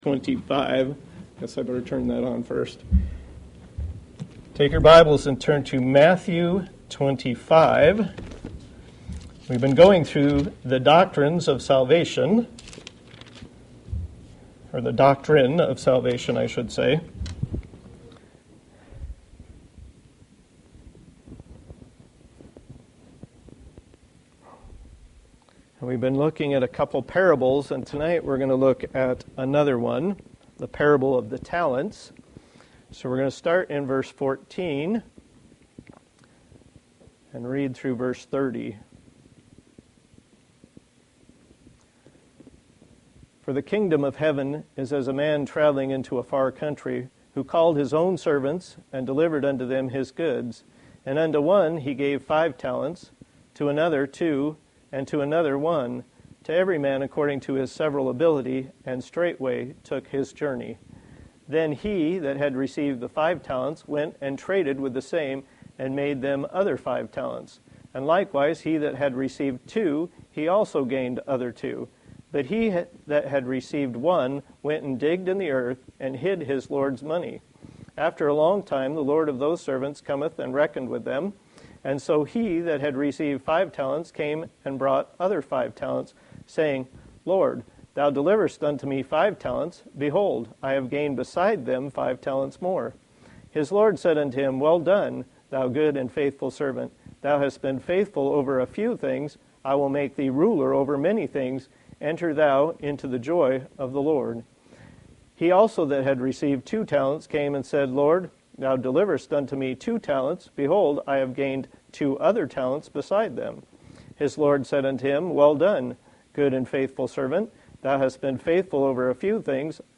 Passage: Matthew 25:14-30 Service Type: Thursday Evening